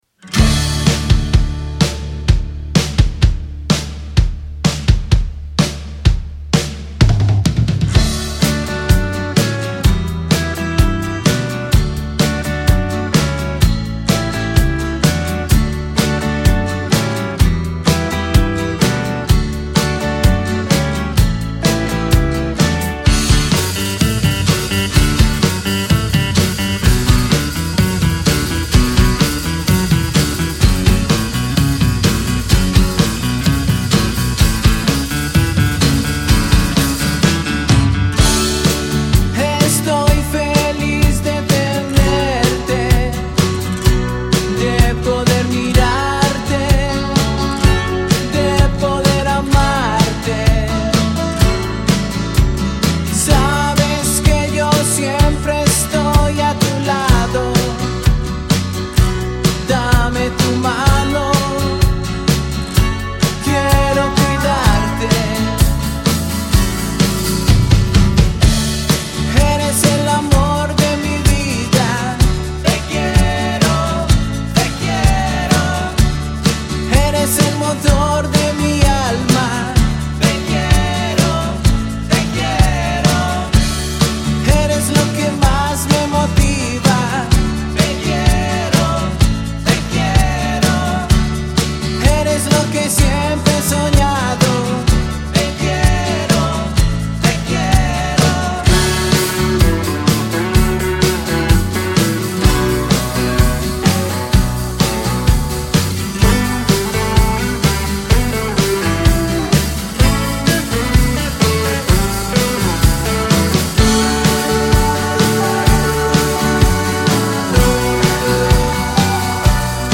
Pop rock